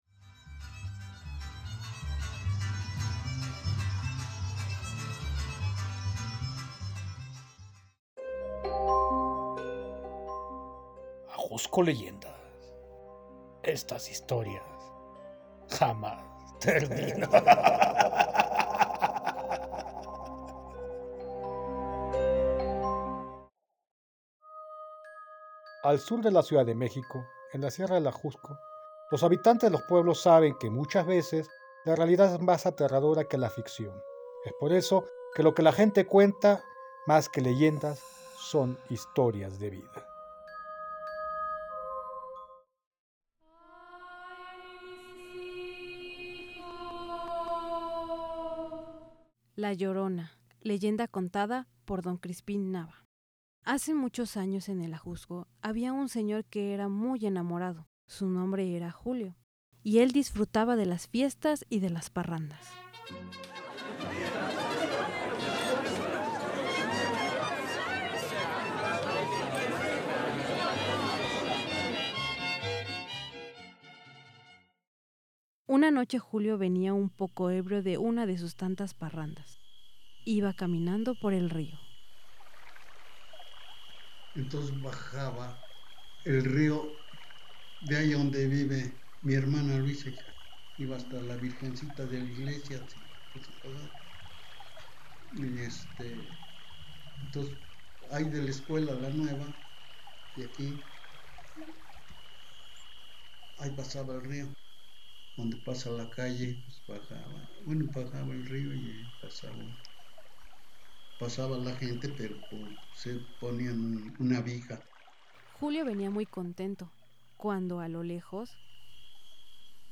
Otros títulos : leyendas de Santo Tomás Ajusco en una serie de podcast de documental sonoro"
Documental sonoro
Estos podcasts incluirán leyendas contadas de viva voz por los pobladores, así como dramatizaciones con narradores y efectos de sonido.